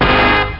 Horn Hit Loud Sound Effect
Download a high-quality horn hit loud sound effect.
horn-hit-loud.mp3